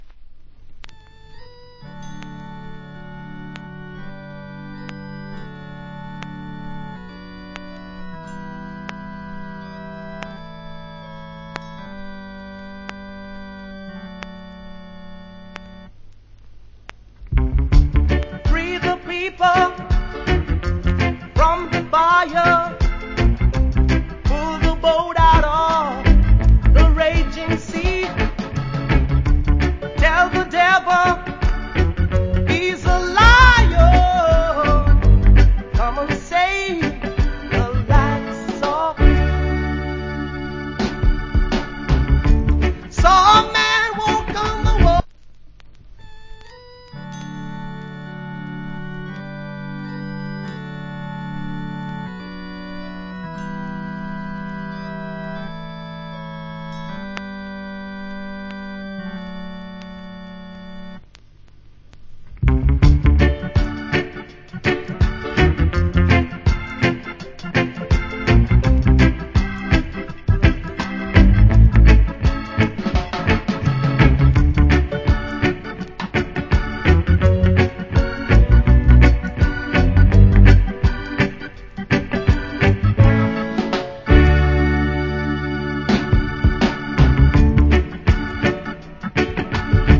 Good Reggae Vocal.